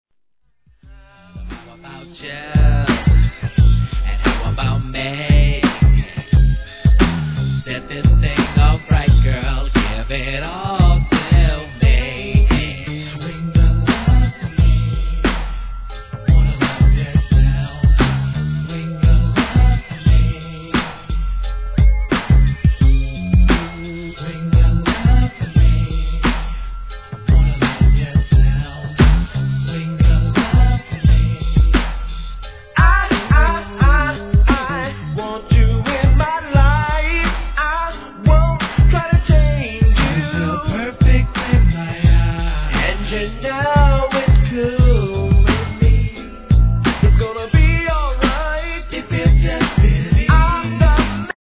G-RAP/WEST COAST/SOUTH
ミディアムなメロ〜トラックにコーラスとRAPの絶妙なコンビネーション、マイナーG!!